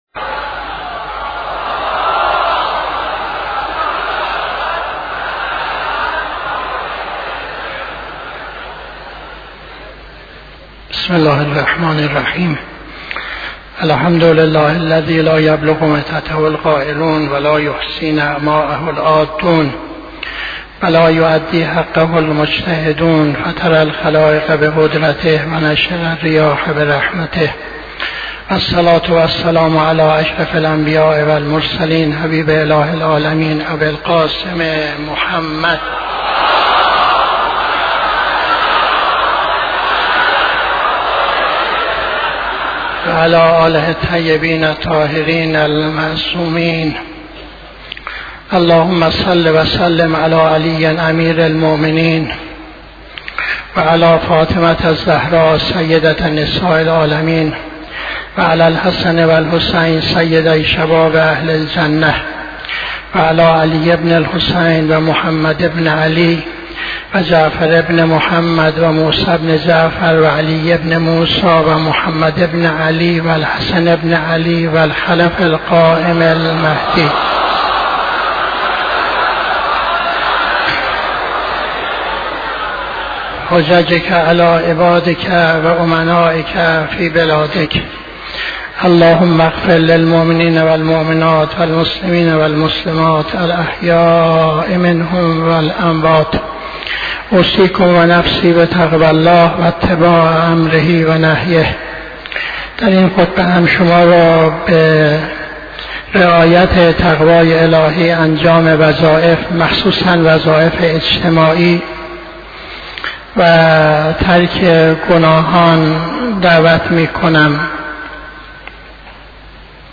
خطبه دوم نماز جمعه 16-12-81